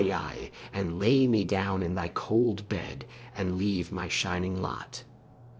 libritts_r_valle.wav